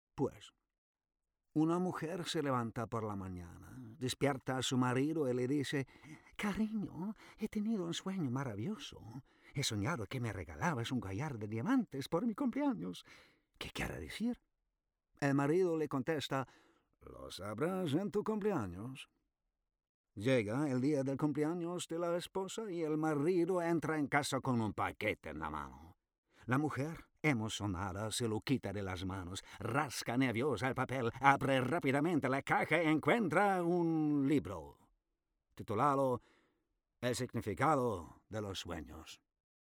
Sprecherdemos
Der Entertainer: Ein Witz